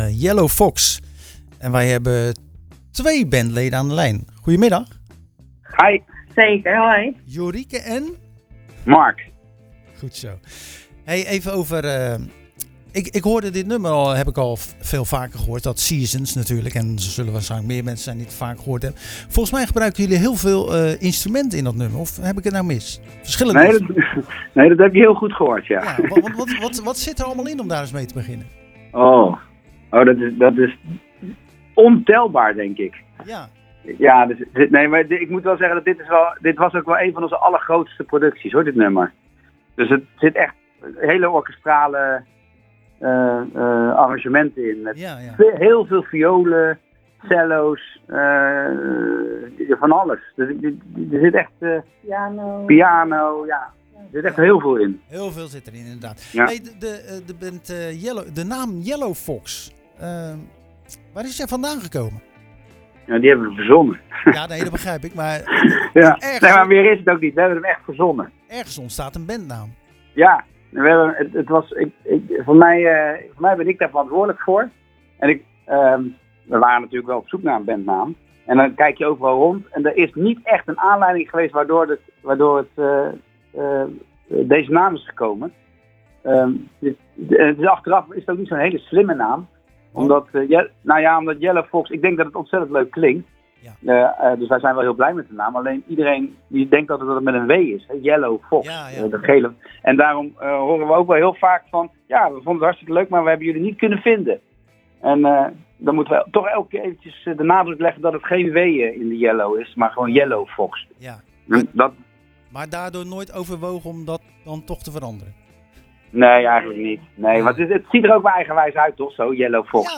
Tijdens de wekelijkse uitzending van Zwaardvis spraken we de Haagse band Yellofox over o.a. hun a.s. optreden tijdens Delft Blues dat van 23- t/m 26 februari plaatsvindt in de Delftse binnenstad.�Het is de 23e editie van het festival dat na twee coronajaren weer terug is op de agenda.